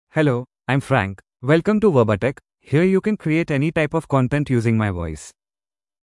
Frank — Male English (India) AI Voice | TTS, Voice Cloning & Video | Verbatik AI
MaleEnglish (India)
Frank is a male AI voice for English (India).
Voice sample
Frank delivers clear pronunciation with authentic India English intonation, making your content sound professionally produced.